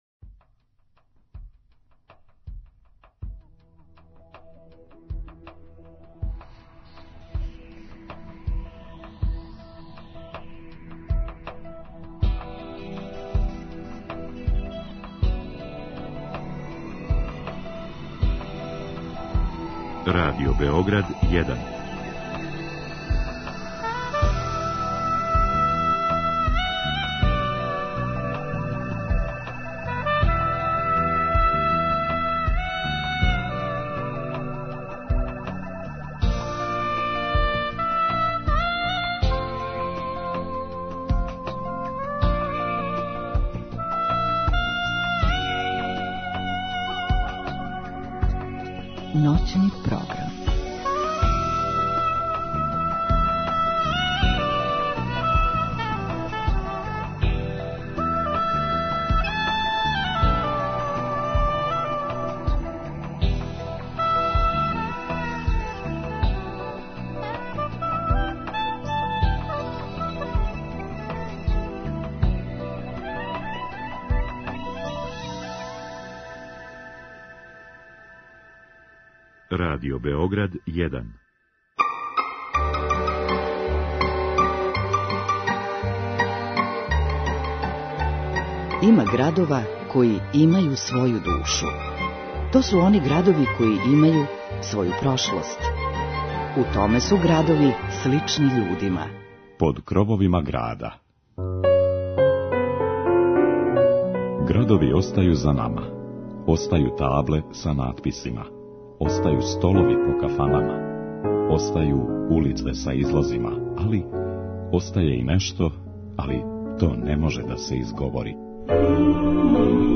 Слушаћете музику познатих интерпретатора рођених у овом граду који одише посебном харизмом различитих култура.